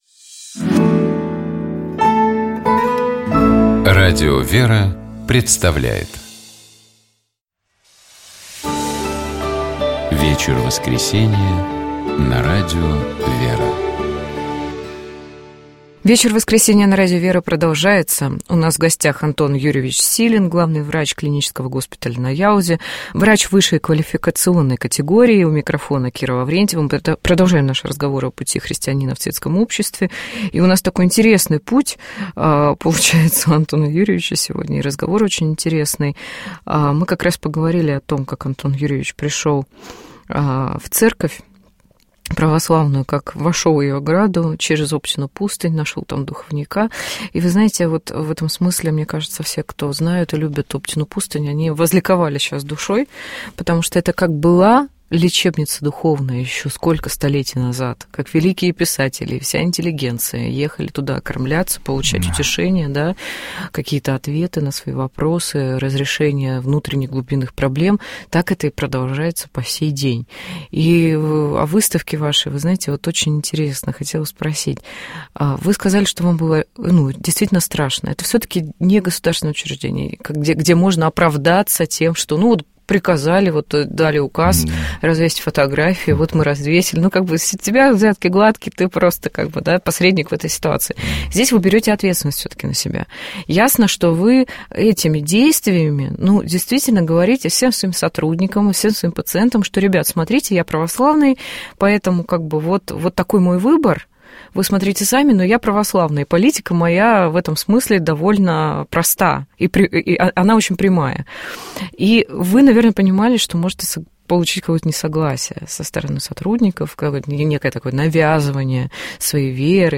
Тема у нас такая непростая, с одной стороны очень широкая, а с другой, это всегда портретное интересное интервью.